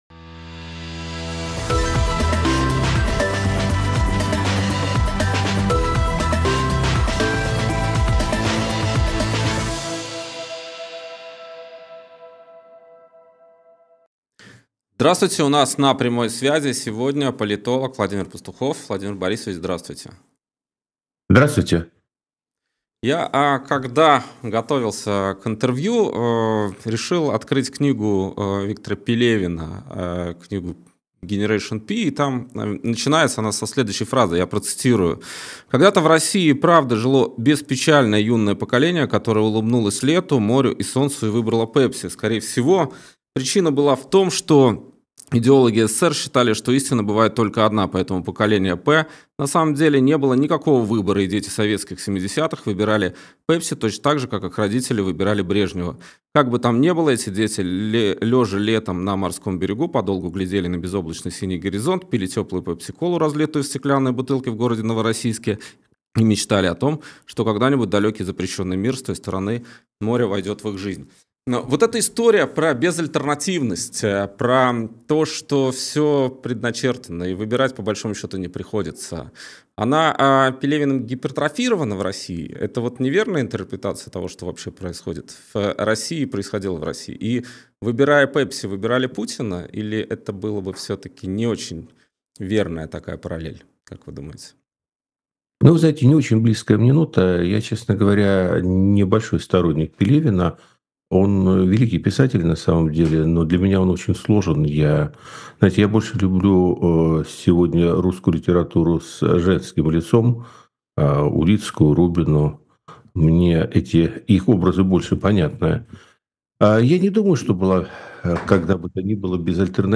Интервью на канале «И грянул Грэм» 9 января 2025